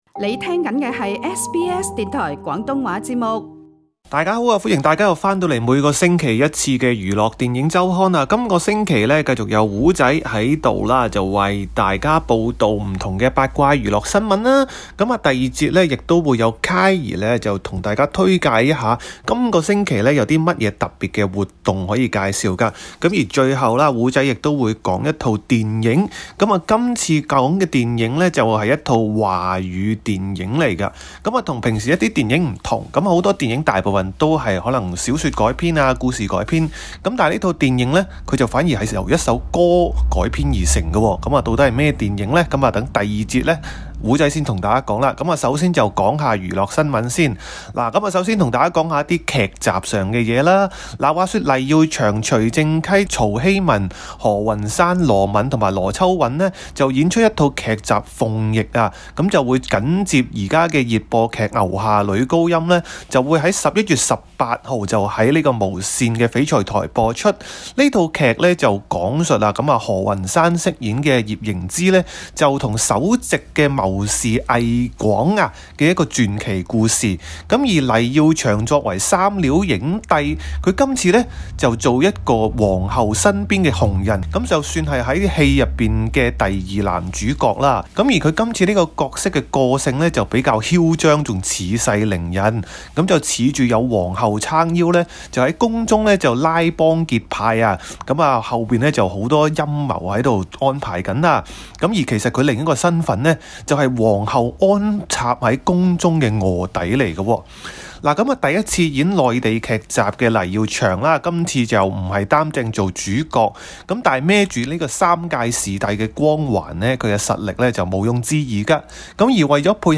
兩位年青主持將在節目中，為各位詳細介紹，他們亦會推介維州及南澳舉行的其他活動，以及介紹華語電影及報導數則娛樂消息。